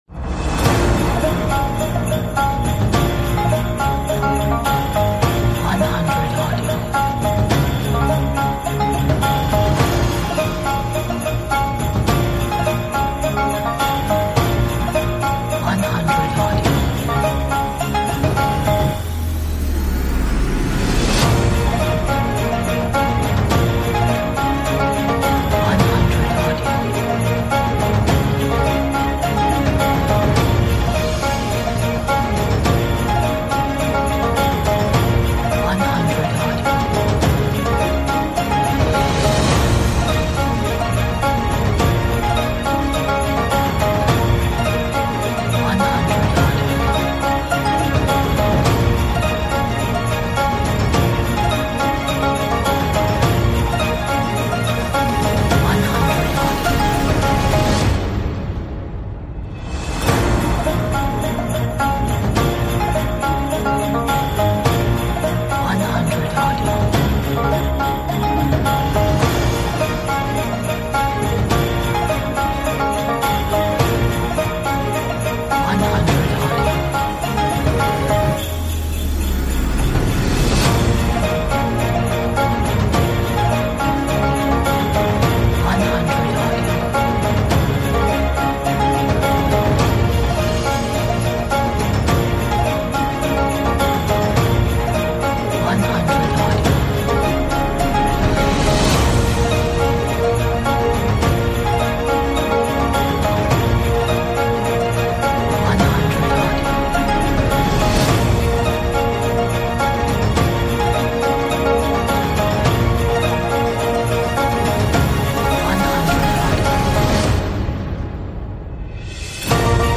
traditional Chinese New Year track